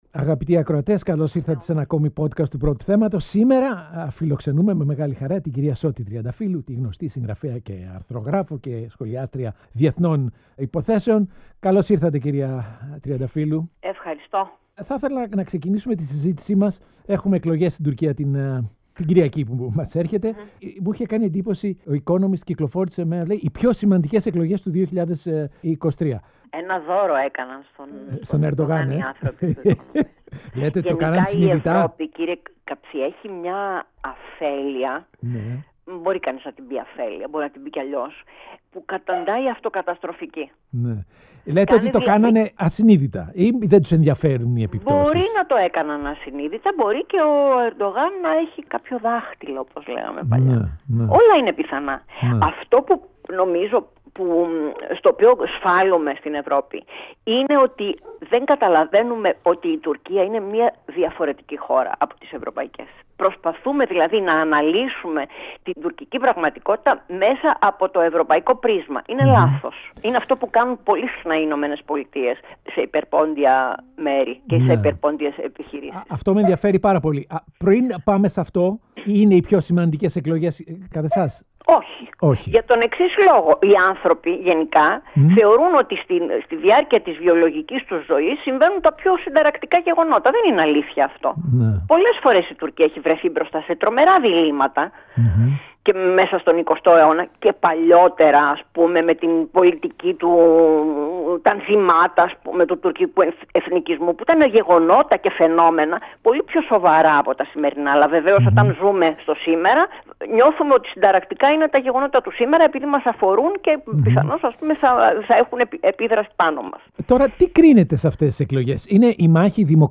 O Παντελής Καψής συζητά με την Σώτη Τριανταφύλλου: Γιατί η Ευρώπη κάνει λάθος με την Τουρκία